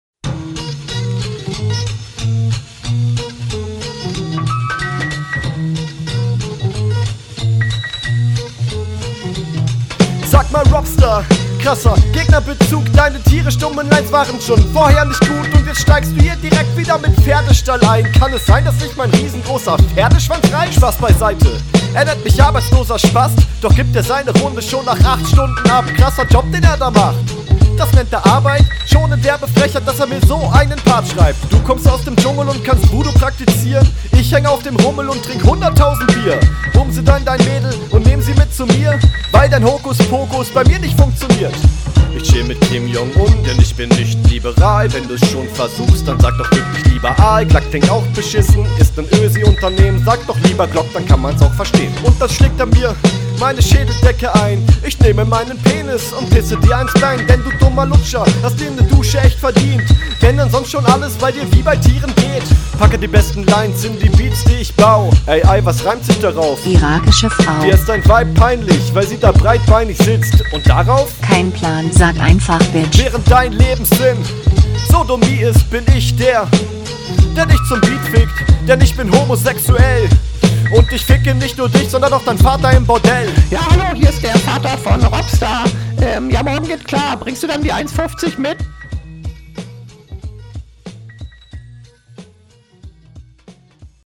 Klingt direkt viel angenehmer weil mir die S-Laute keine Schmerzen bereiten, was aber auch auffällt …